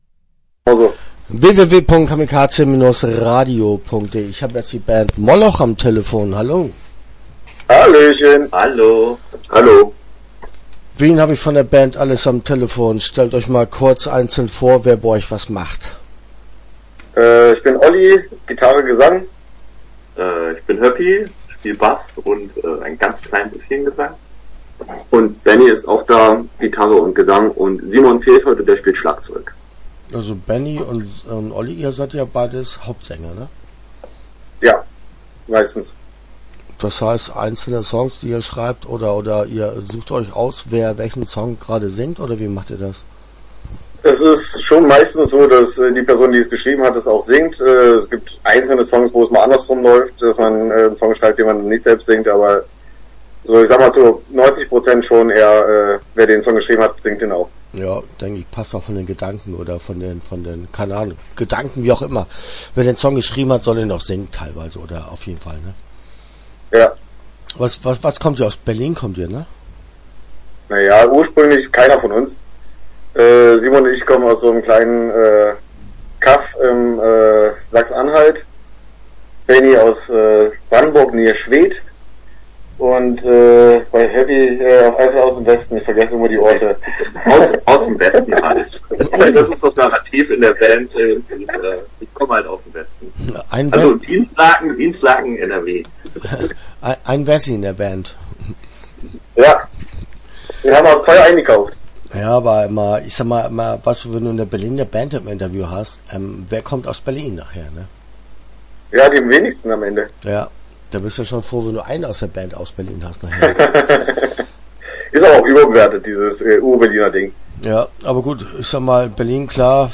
Moloch - Interview Teil 1 (11:05)